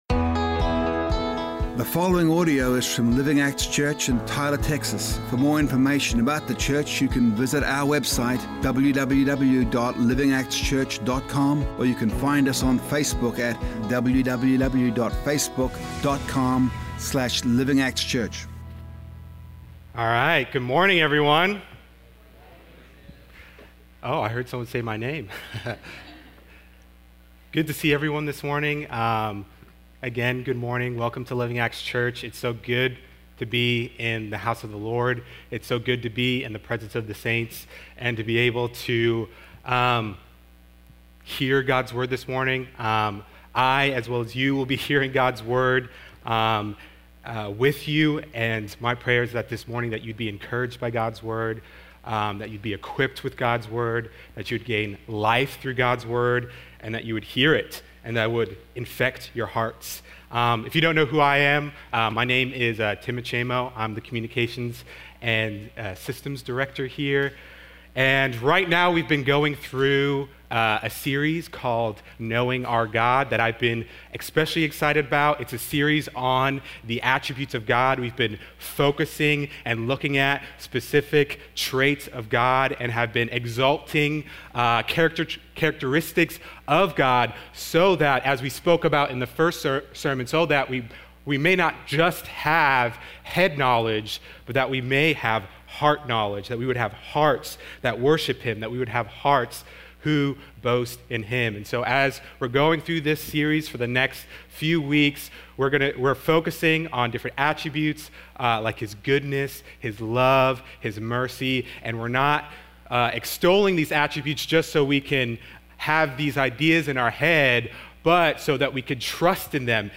A message from the series "Knowing Our God."